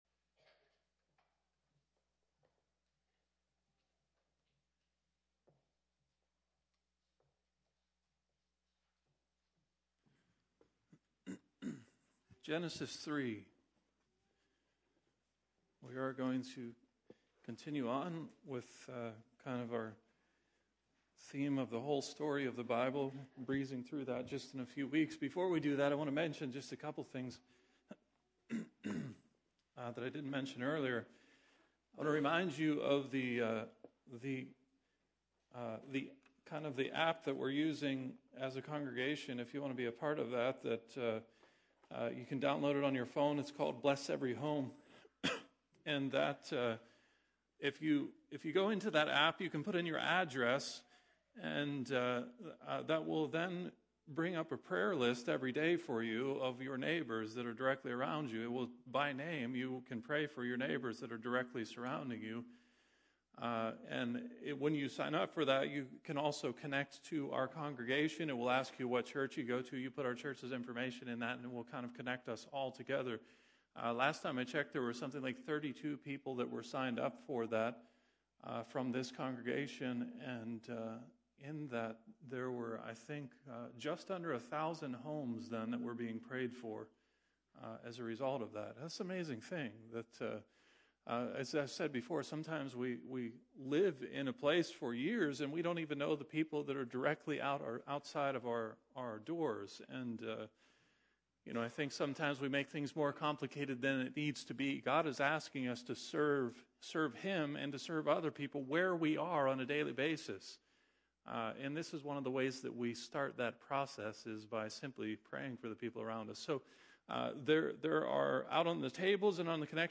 Sermons | Barrs Mill Church of God